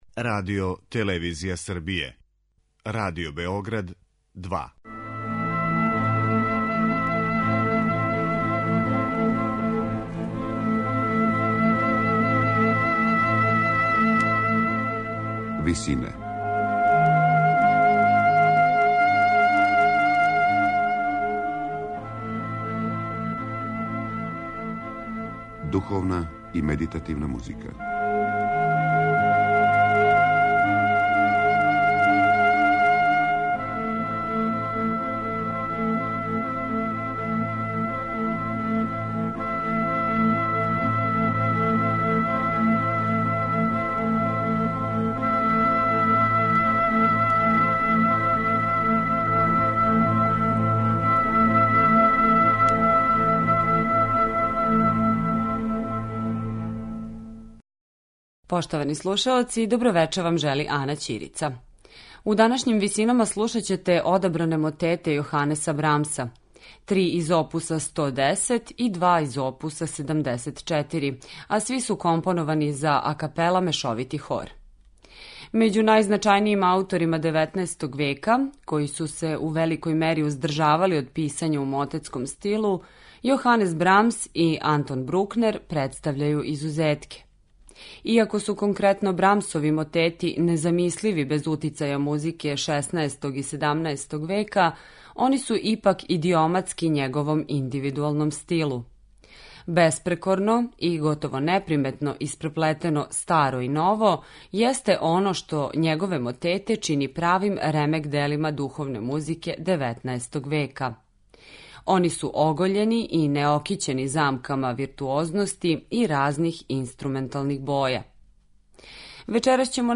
Брамсови мотети
На крају програма, у ВИСИНАМА представљамо медитативне и духовне композиције аутора свих конфесија и епоха.